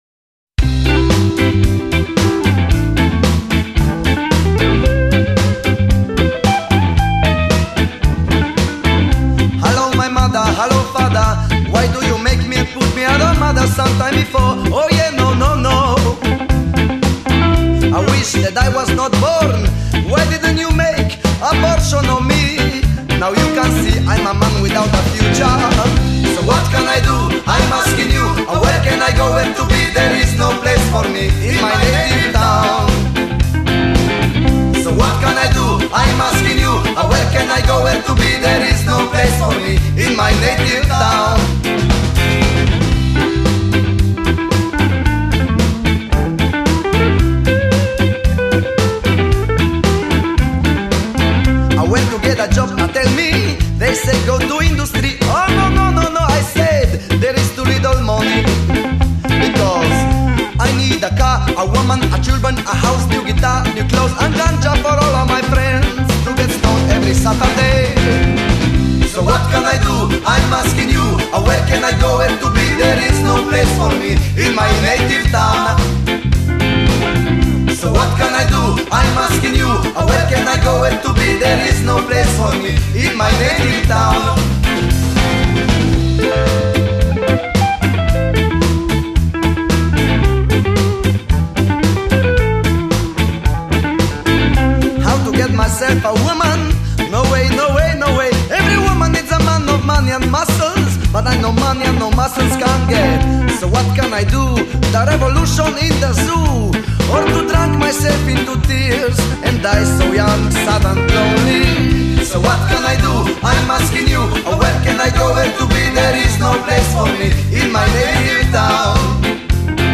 glas
kitara
bobni